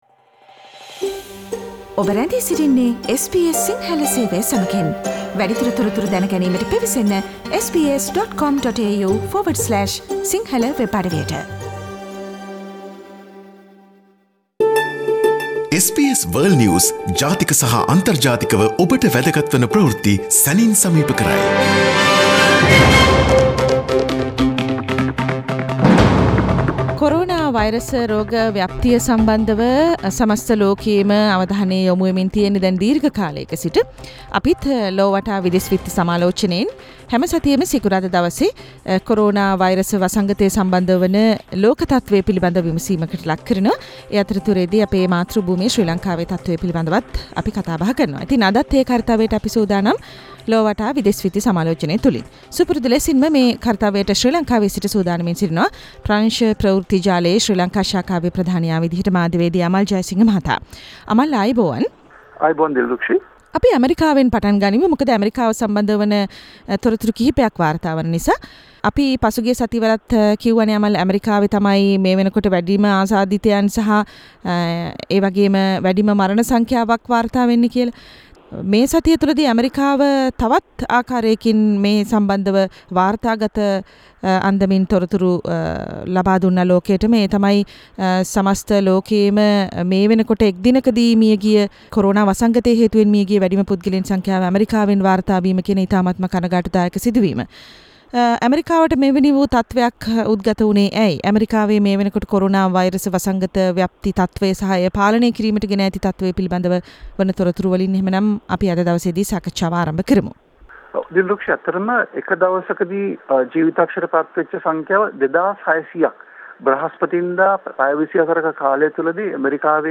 weekly world news wrap - Source: SBS Sinhala radio